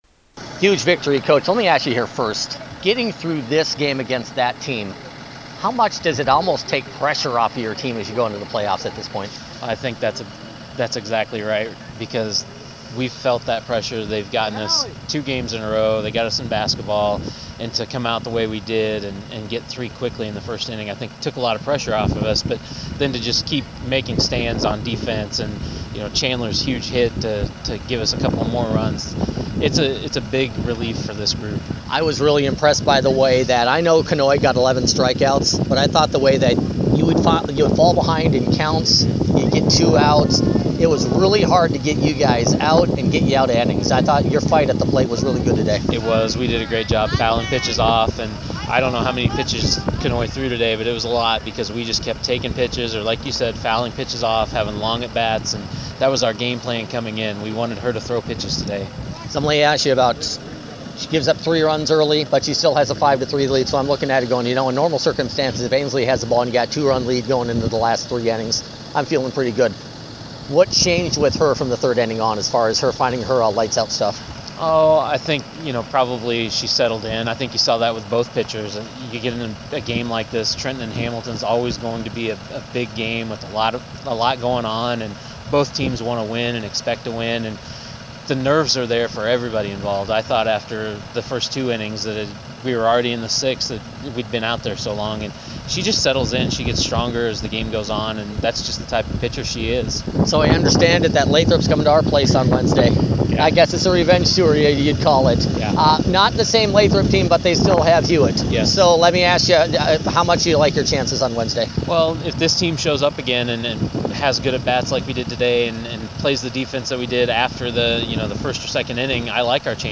Sports Director